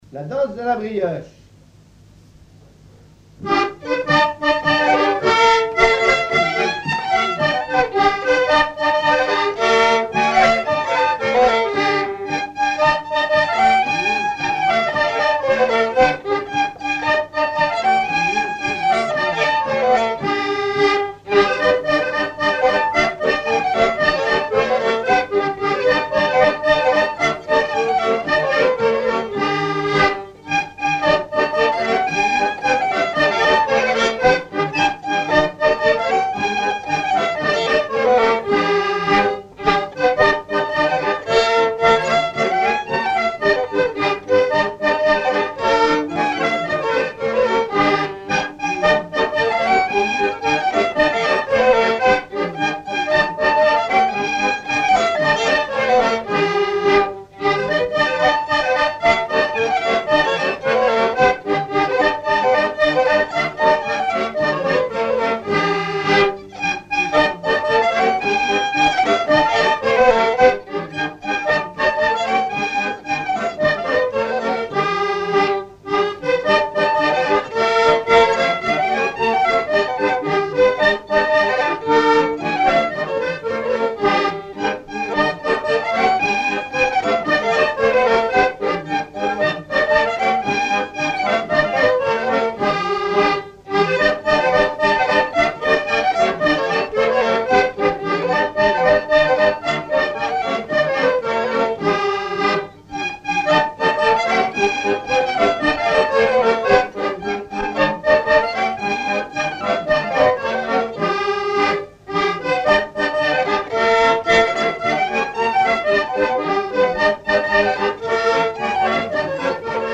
Rochetrejoux
branle
Couplets à danser
Pièce musicale inédite